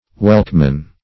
welchman - definition of welchman - synonyms, pronunciation, spelling from Free Dictionary Search Result for " welchman" : The Collaborative International Dictionary of English v.0.48: Welchman \Welch"man\, n. See Welshman .